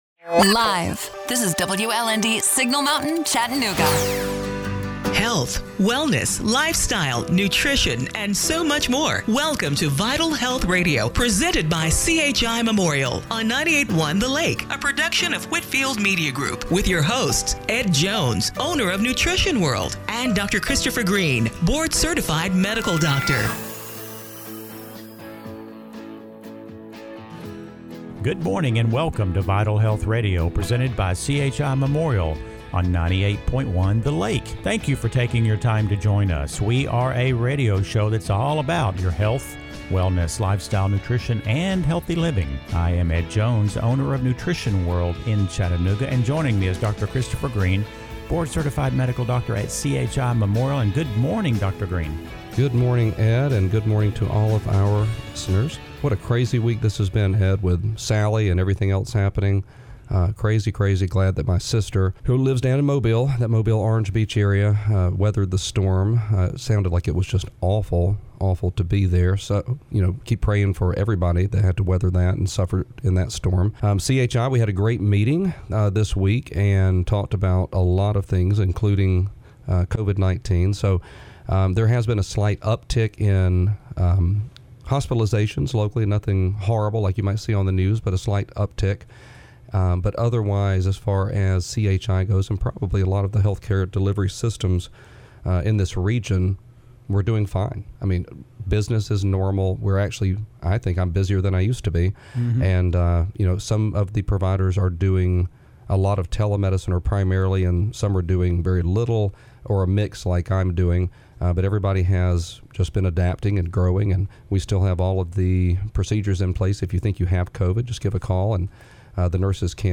September 20, 2020 – Radio Show - Vital Health Radio